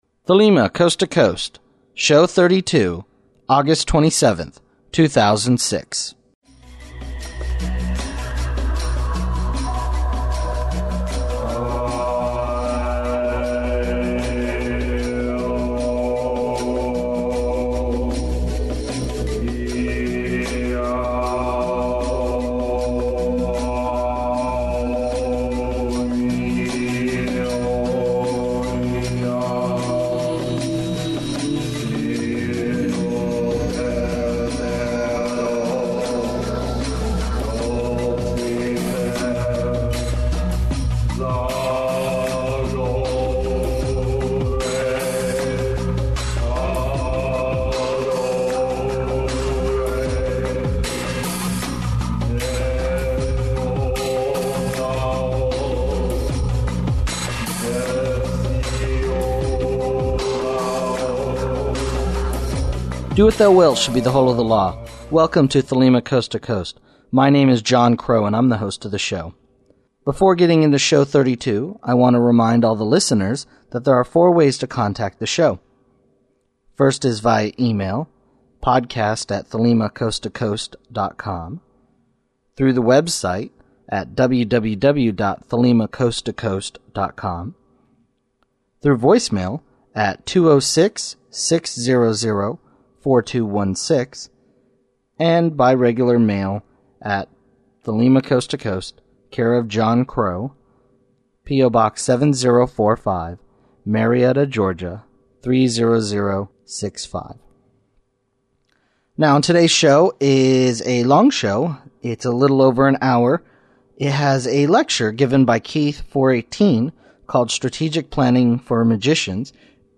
Interview with author